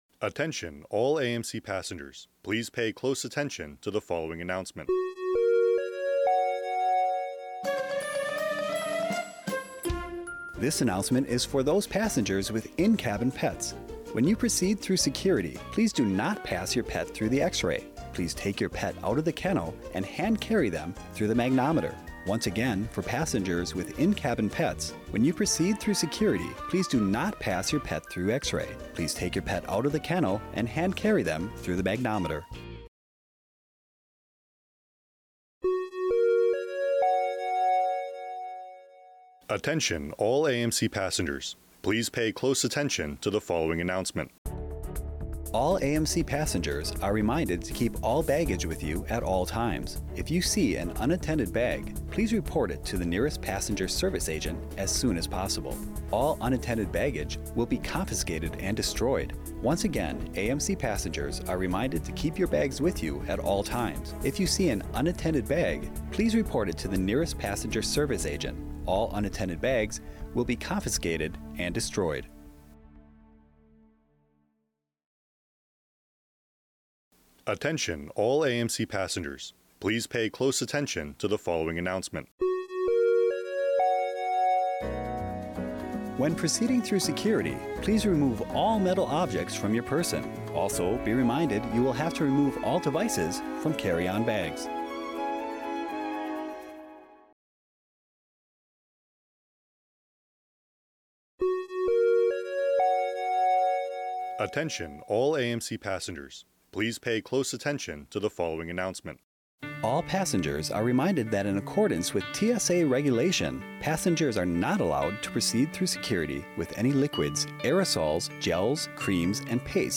This readout contains messaging about TSA Regulations, securing your luggage, protecting your pets and tips for moving through security efficiently, narrated by service members assigned to Media Bureau Japan.